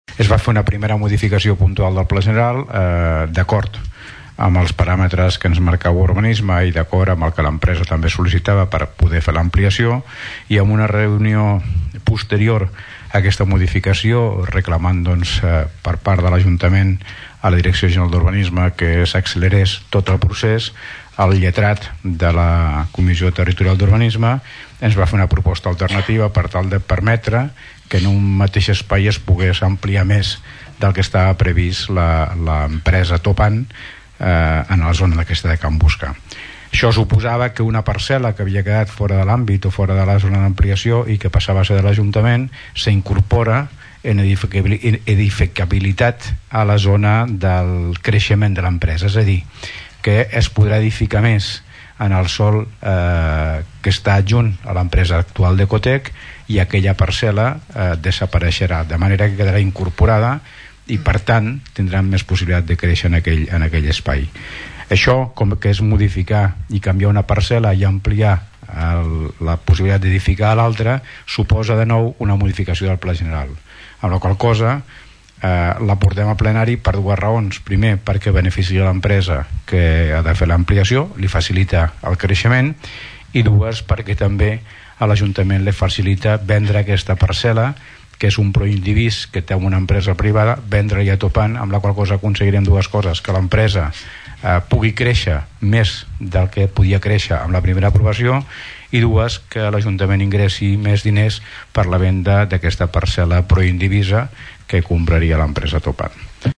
Explicava aquest procés administratiu i urbanístic, l’alcalde de Tordera, Joan Carles Garcia.